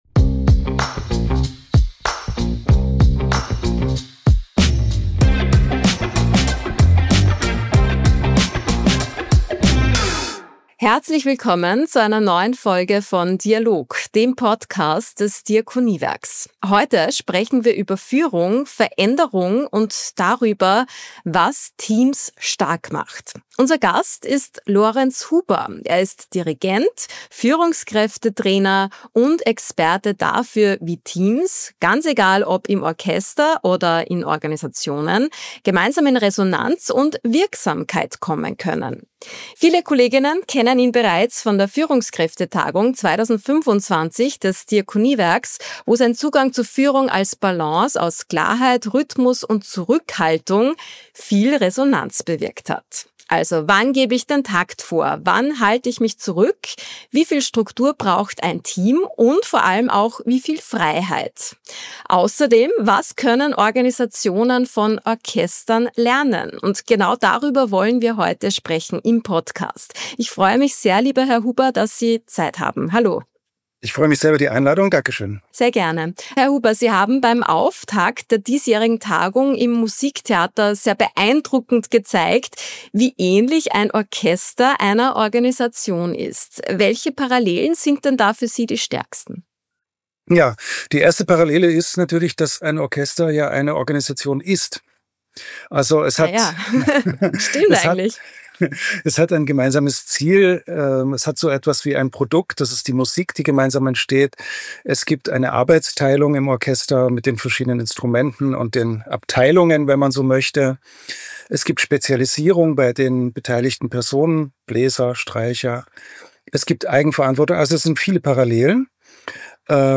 Ein Gespräch über Rollen, Verantwortung, Teamdynamiken und darüber, was Menschen brauchen, um wirksam zusammenzuarbeiten.